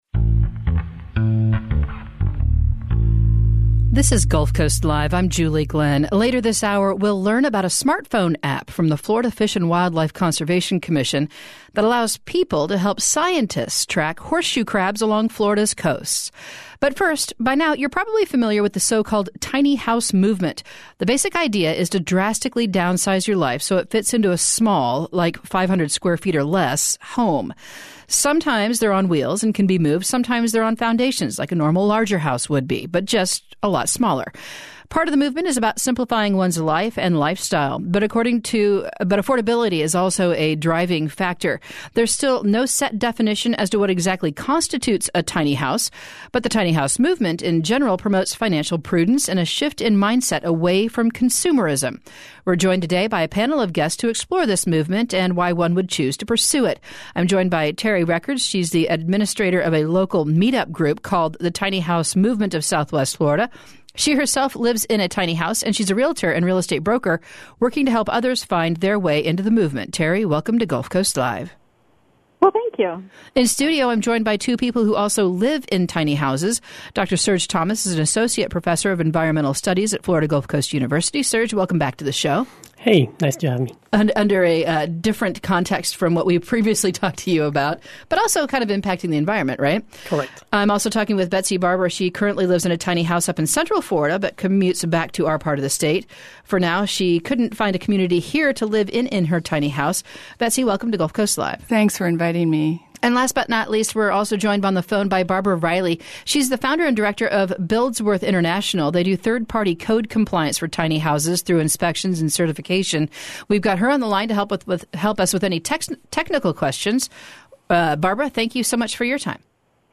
We’re joined by a panel of guests to explore this movement, and why one would choose to pursue it.